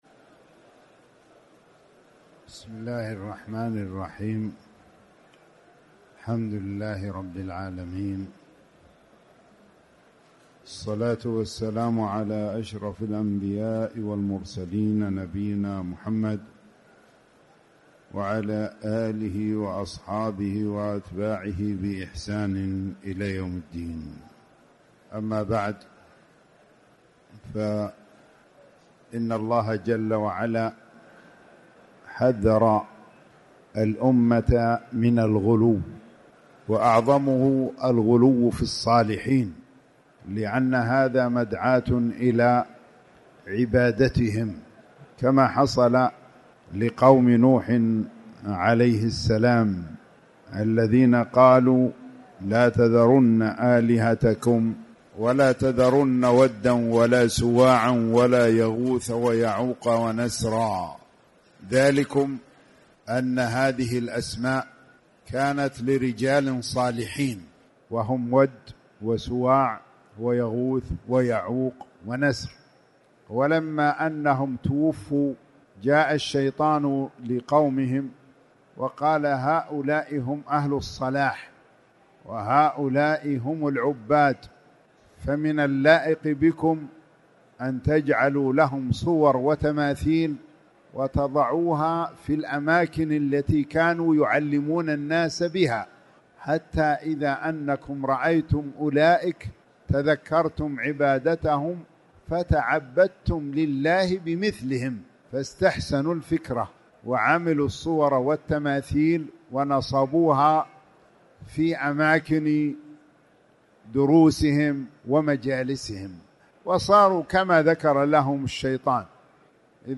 تاريخ النشر ٢٤ رمضان ١٤٣٨ هـ المكان: المسجد الحرام الشيخ